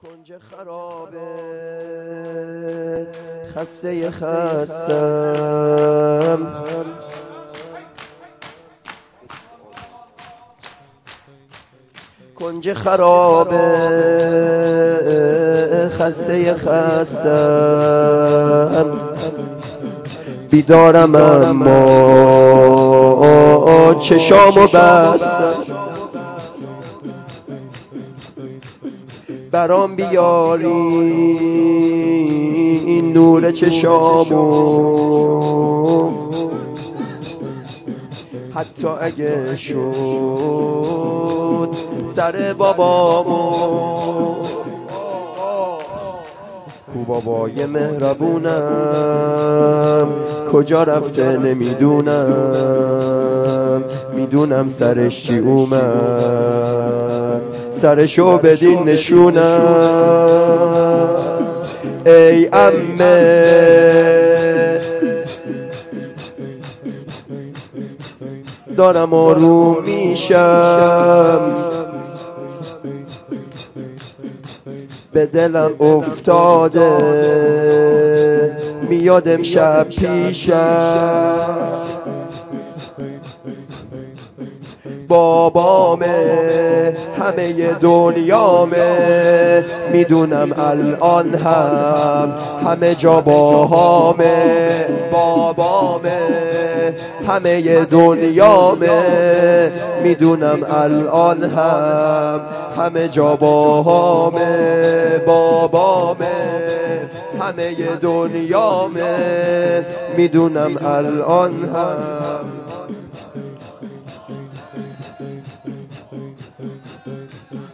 شب سوم ماه محرم